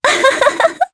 Yuria-Vox_Happy3_jp.wav